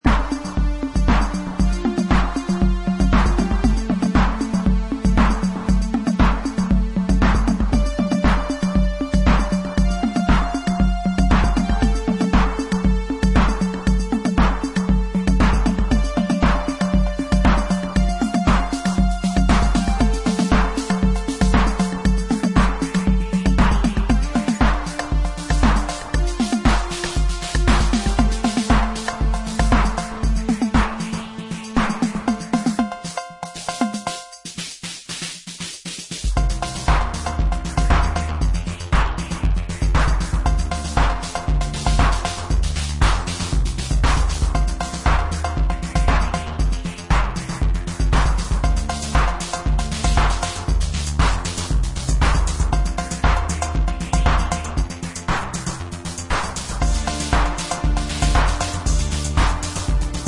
Lovely vintage Chicago house album